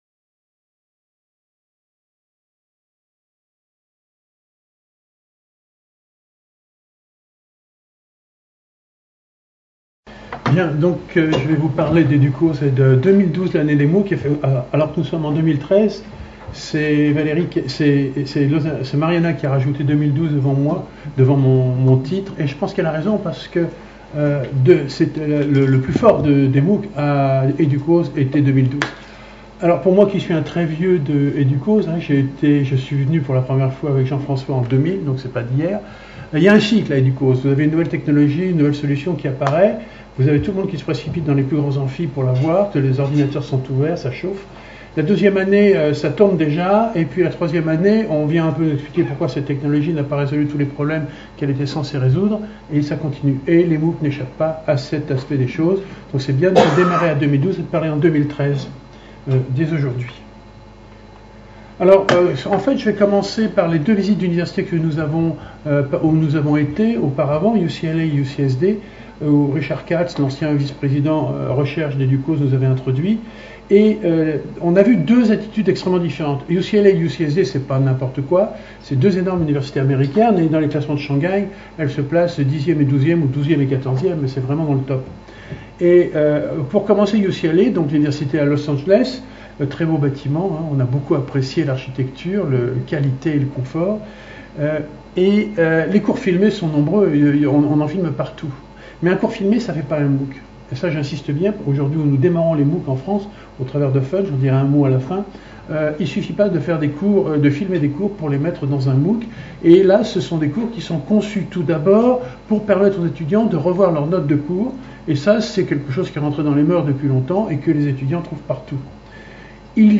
Restitution de la mission à EDUCAUSE organisée par l’AMUE le 8 novembre 2013 à la maison des universités. Les participants de la délégation française partagent les principaux sujets abordés à EDUCAUSE 2013 et les bonnes pratiques identifiées à l’occasion de pré-visites aux universités américaines.